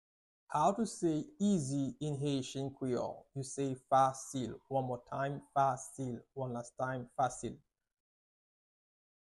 Listen to and watch “Fasil” audio pronunciation in Haitian Creole by a native Haitian  in the video below:
6.How-to-say-Easy-in-Haitian-Creole-–-Fasil-pronunciation.mp3